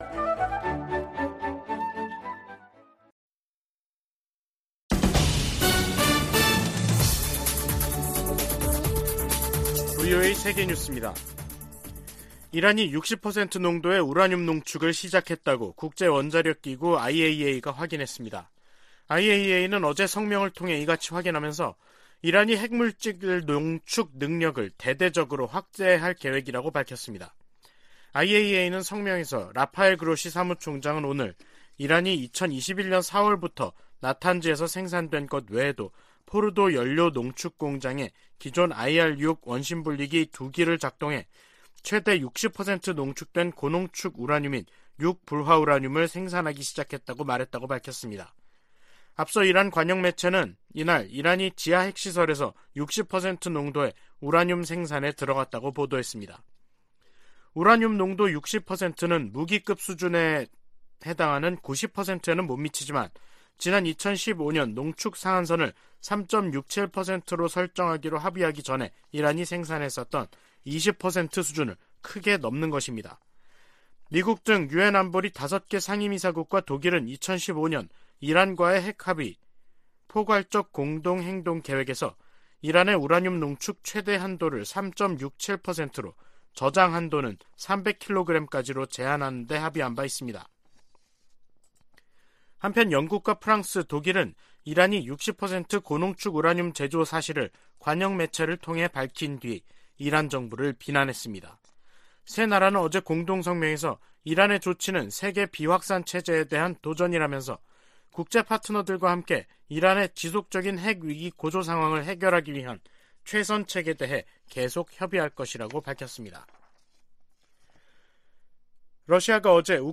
VOA 한국어 간판 뉴스 프로그램 '뉴스 투데이', 2022년 11월 23일 3부 방송입니다. 백악관의 존 커비 전략소통조정관은 중국이 북한에 도발적인 행동을 멈추도록 할 수 있는 압박을 가하지 않고 있다고 지적했습니다. 중국이 북한 불법무기 프로그램 관련 유엔 안보리 결의를 전면 이행해야 한다고 미 국방장관이 촉구했습니다.